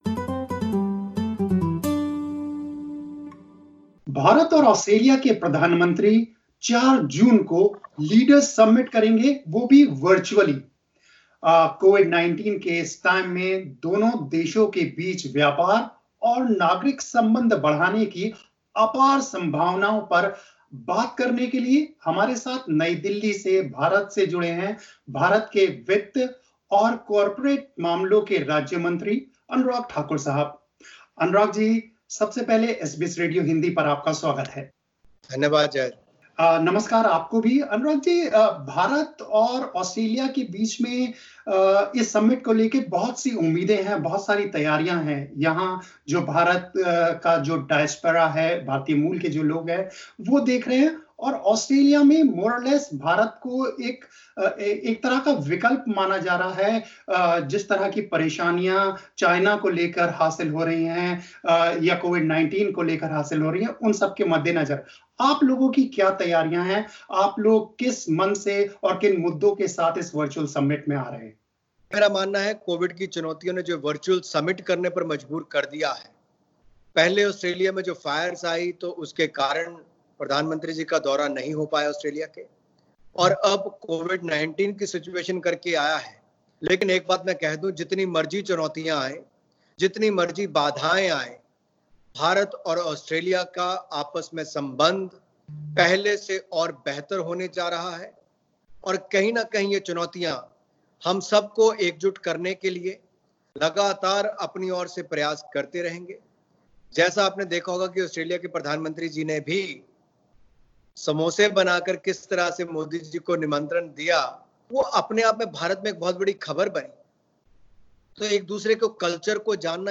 Highlights: First bilateral virtual summit between Australia and India Focus will be on enhancing cooperation in defence technology, cybersecurity and investment in critical infrastructure In an exclusive interview with SBS Hindi, the Indian Minister of State for Finance and Corporate Affairs, Mr Anurag Thakur said India was setting up a special group to grow trade relations.